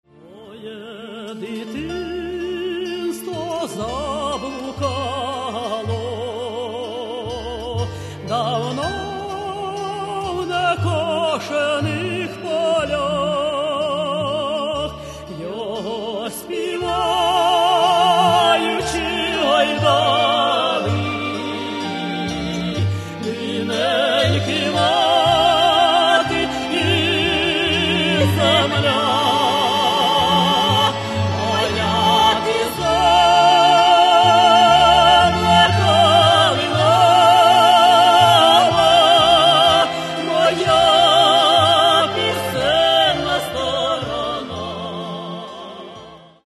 Каталог -> Народная -> Ансамбли народной музыки